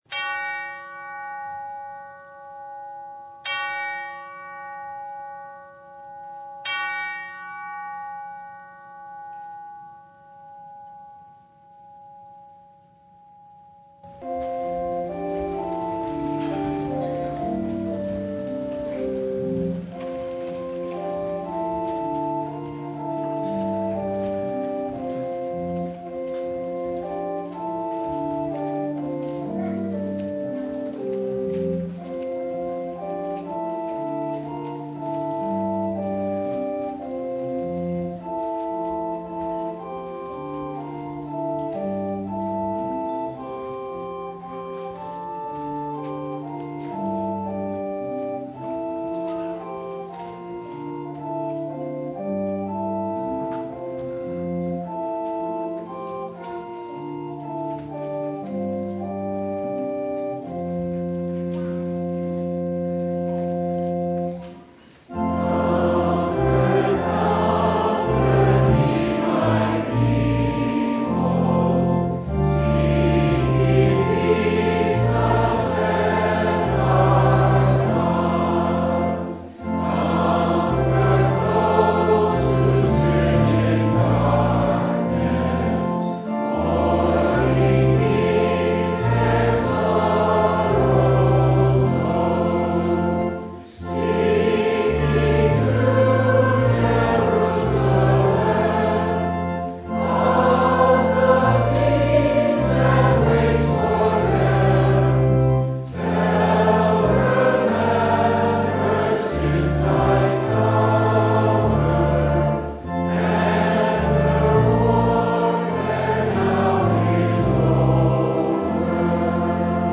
Luke 3:1-20 Service Type: Advent What prepares us for Jesus?